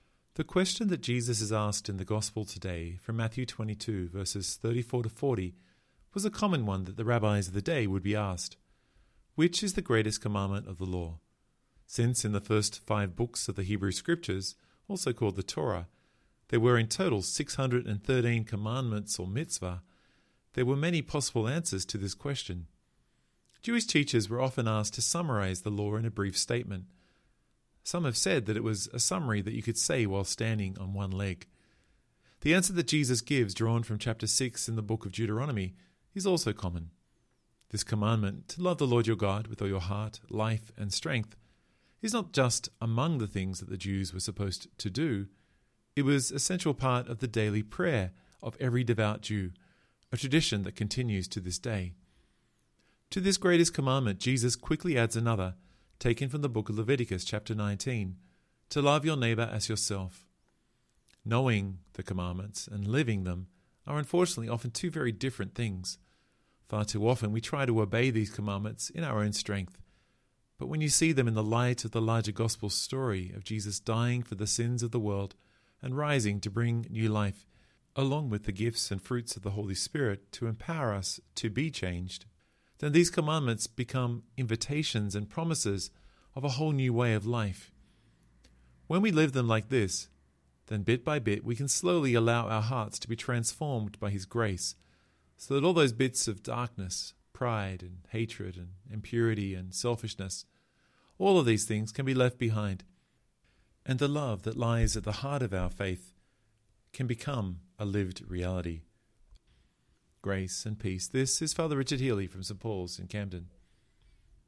Liturgy of the Word 30A – Ordinary Sunday 30